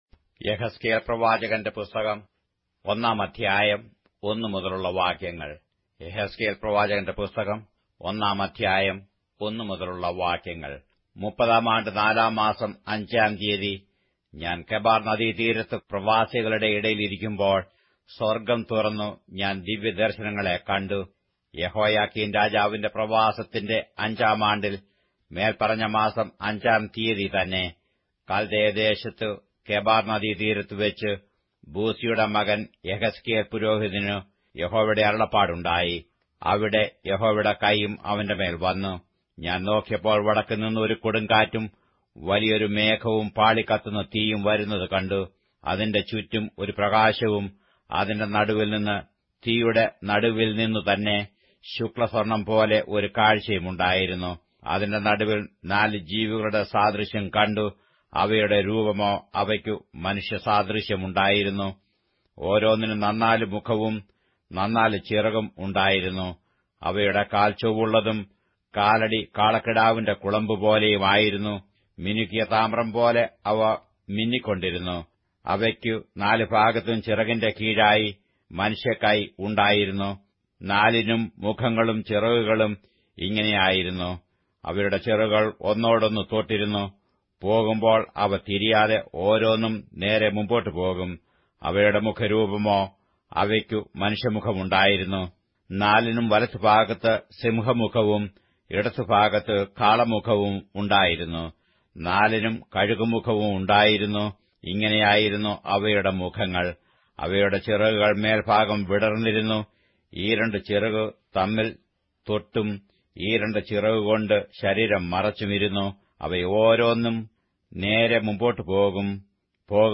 Malayalam Audio Bible - Ezekiel 4 in Irvbn bible version